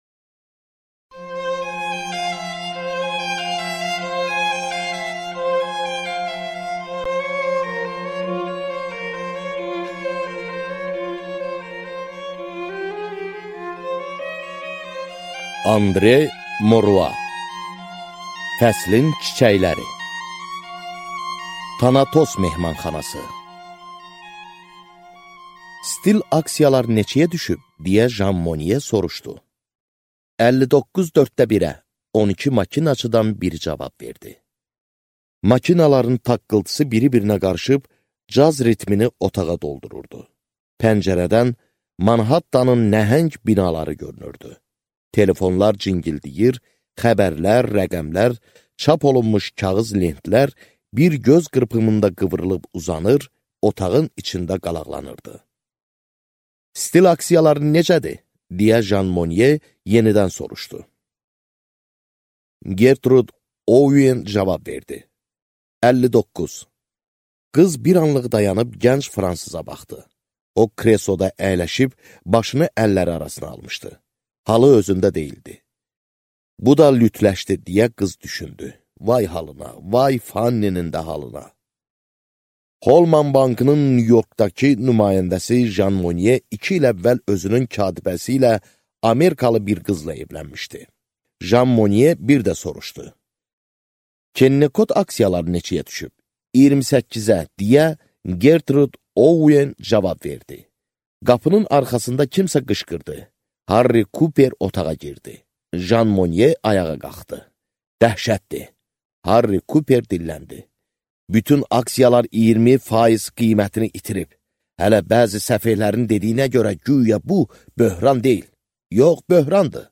Аудиокнига Fəslin çiçəkləri | Библиотека аудиокниг
Прослушать и бесплатно скачать фрагмент аудиокниги